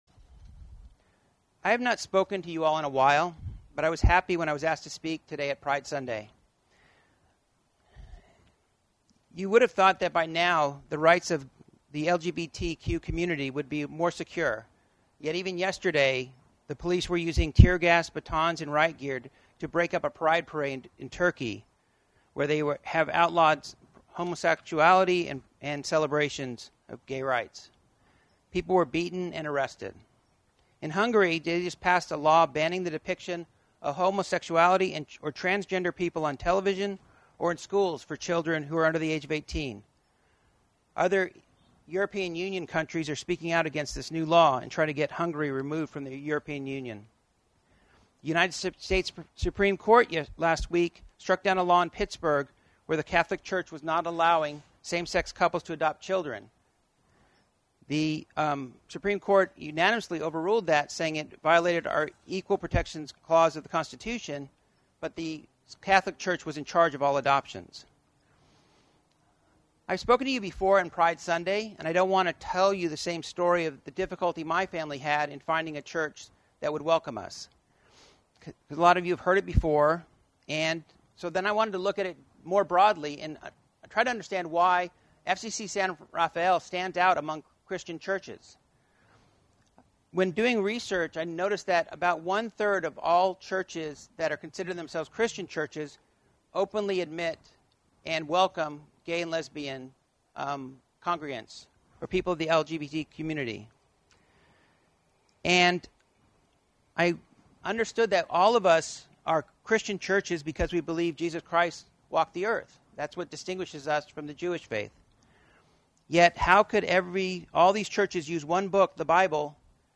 Following the sermon, the congregation joined in the following Pride Litany: